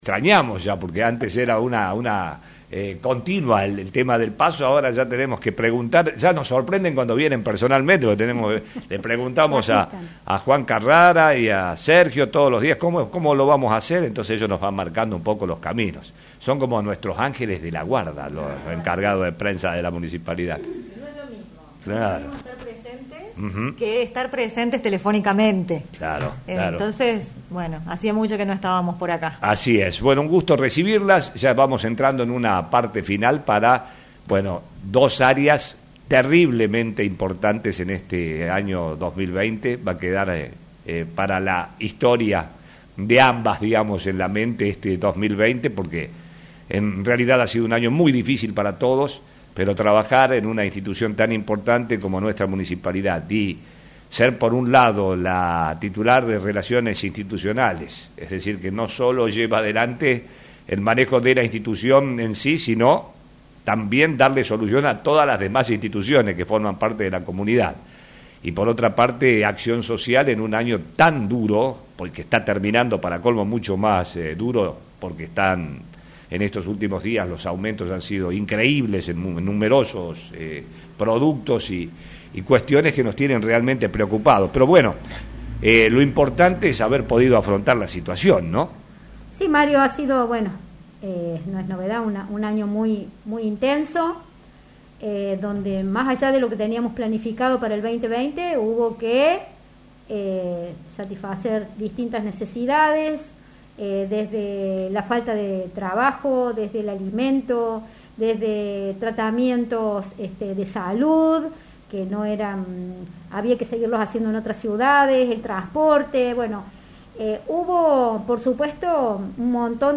visitaron los estudios de Flash FM para comentar detalles de lo que se hizo durante el año y todos los objetivos que se plantean en éstos tiempos de pandemia.